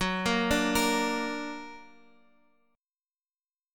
Gb+ chord